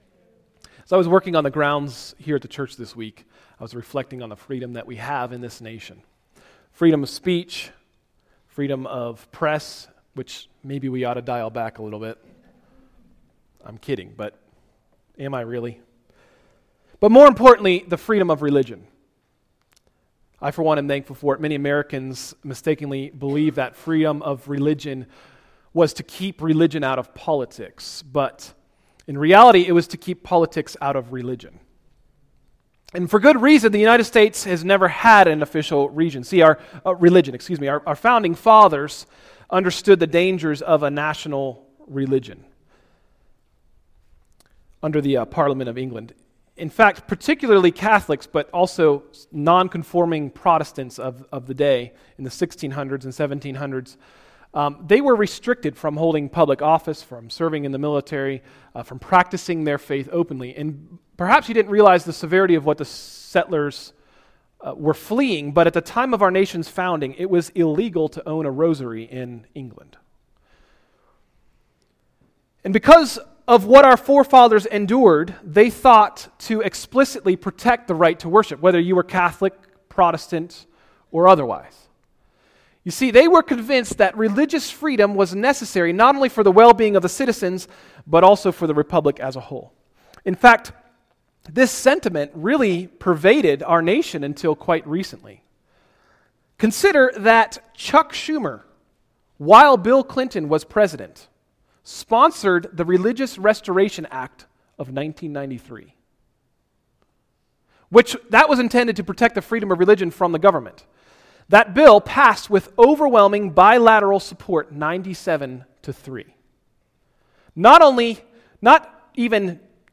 Message: “Let Freedom Serve” – Tried Stone Christian Center